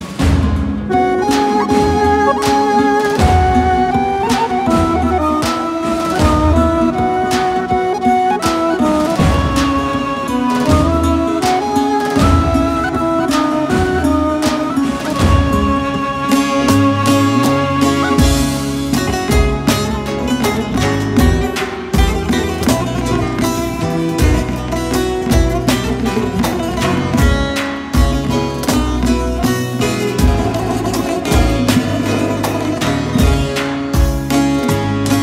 Canakkale-Turkusu-Enstrumental-Fon-Muzigi-mp3cut.net_.mp3